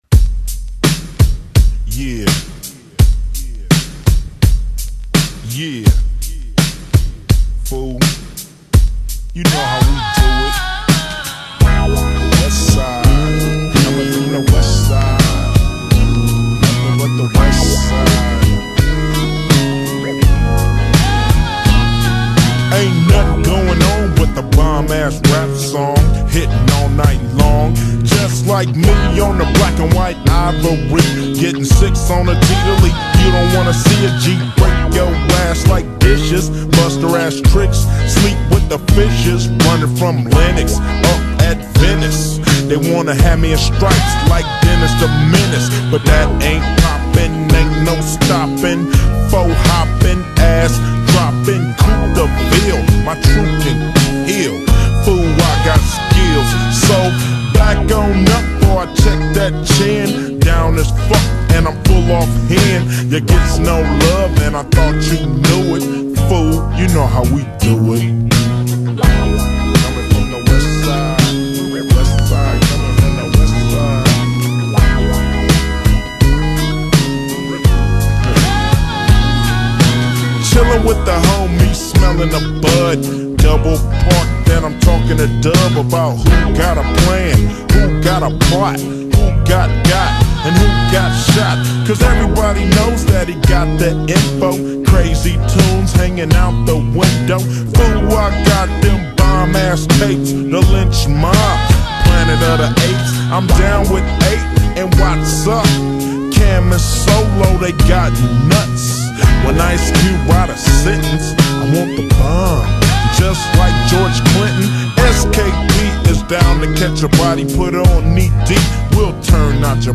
slowed